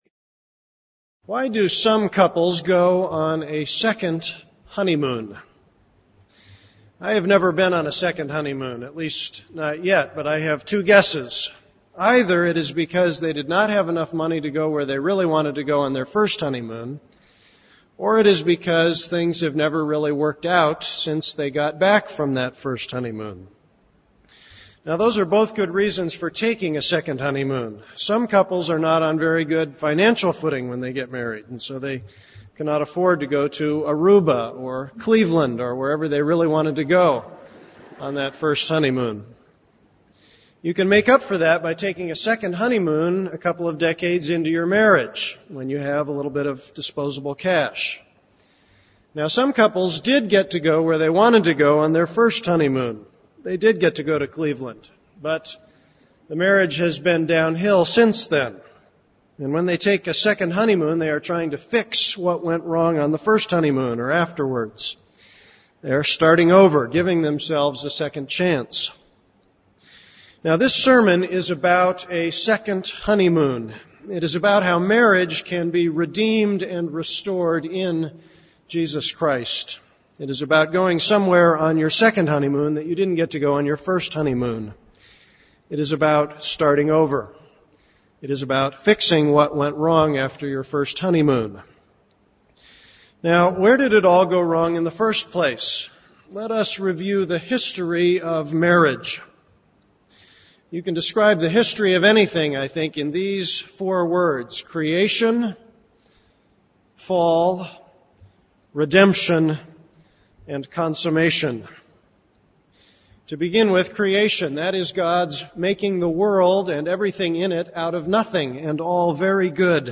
This is a sermon on Jeremiah 33:10-11.